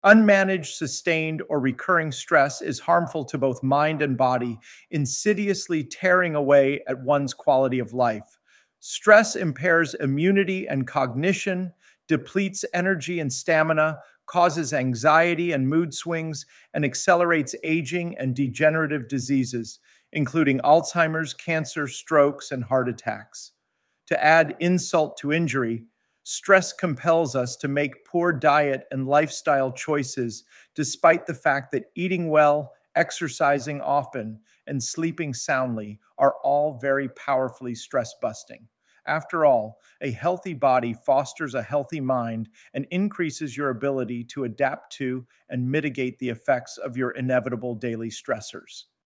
Sample audio EXCERPTS from the audiobooks: